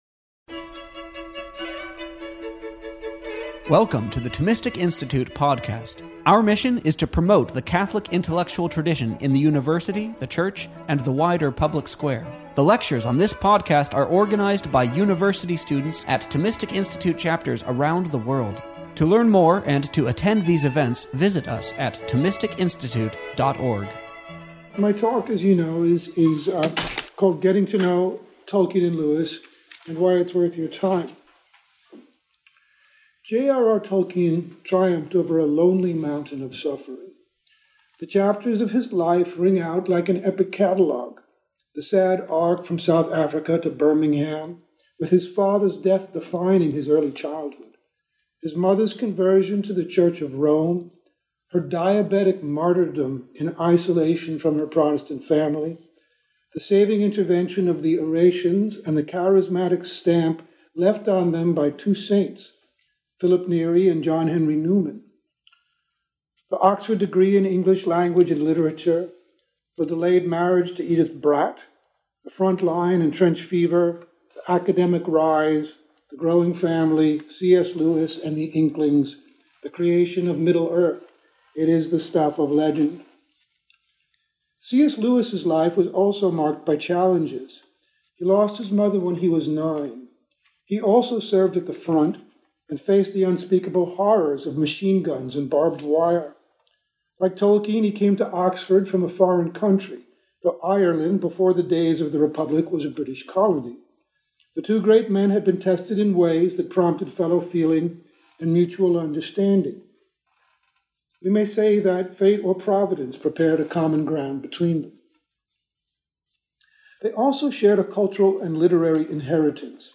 A lecture on October 3, 2015 at Fall Thomistic Circles.